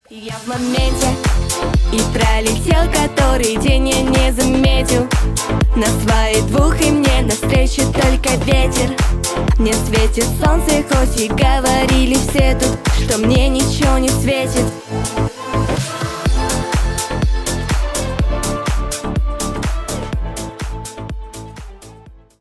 бесплатный рингтон в виде самого яркого фрагмента из песни
Поп Музыка # кавер